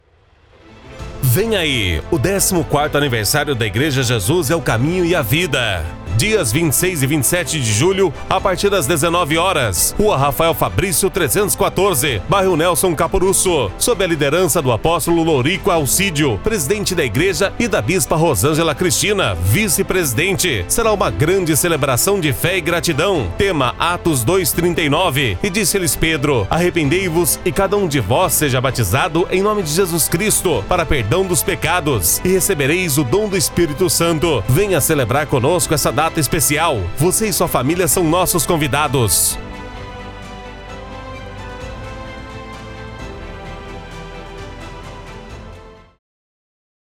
Demo - Igreja: